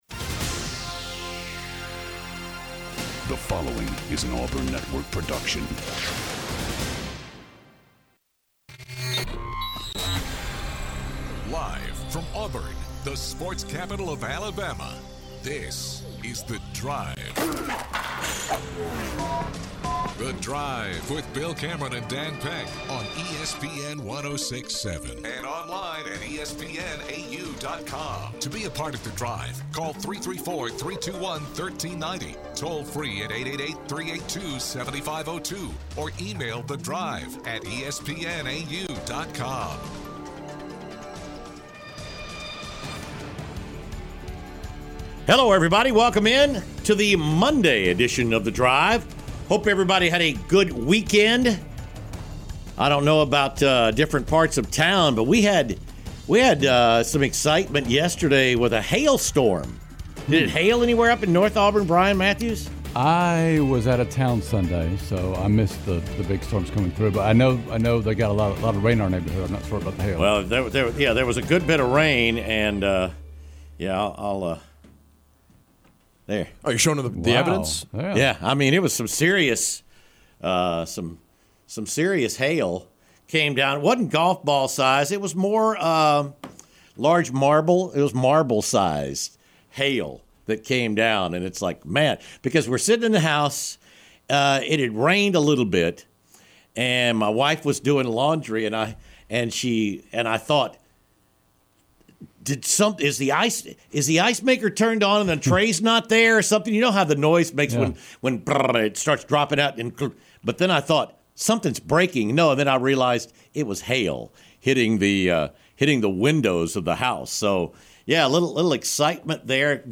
joined in the studio